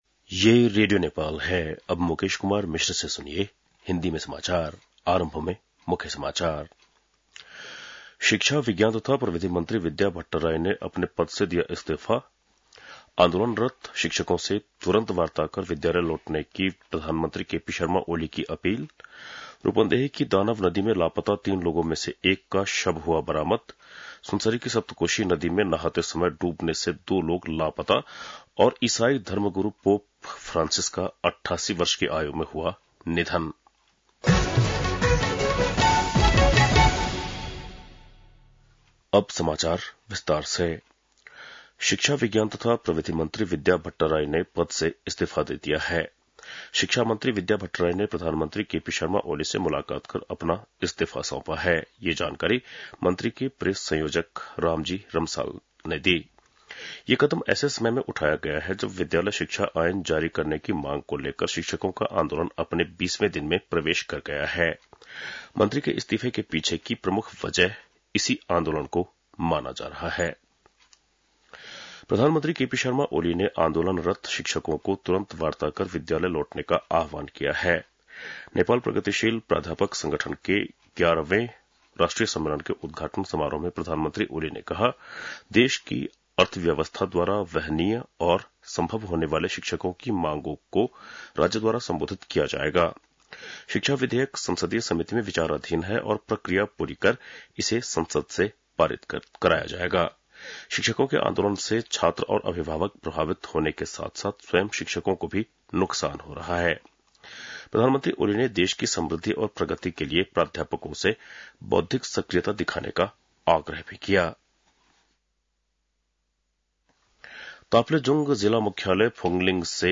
बेलुकी १० बजेको हिन्दी समाचार : ८ वैशाख , २०८२
10-pm-hindi-news-2.mp3